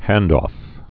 (hăndôf, -ŏf)